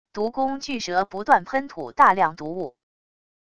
毒攻巨蛇不断喷吐大量毒雾wav音频